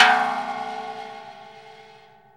METAL HIT 12.wav